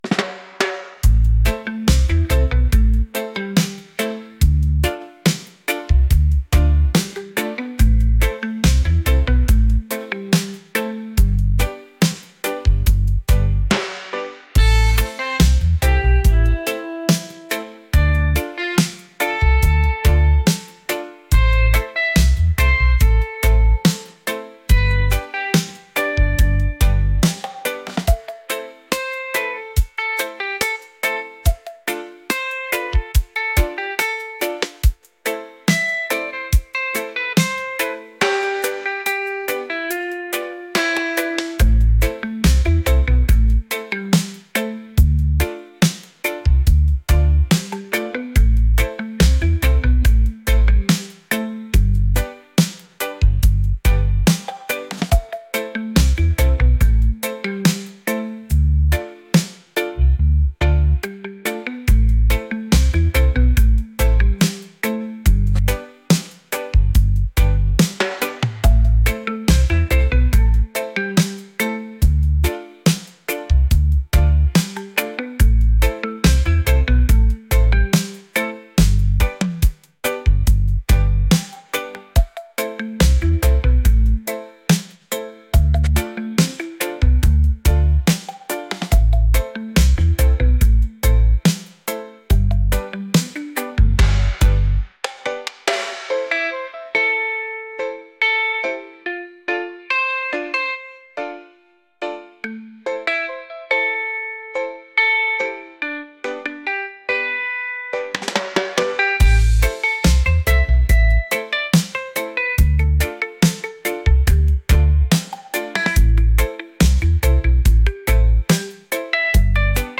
island | reggae